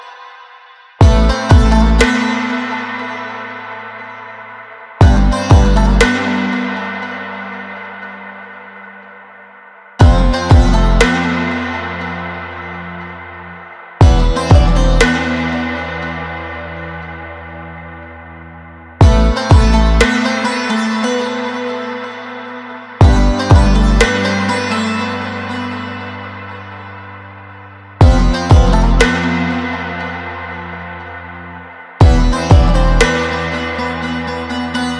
Модульные синтезаторы и глитчи
создают футуристичный саунд
Electronica
2022-03-18 Жанр: Электроника Длительность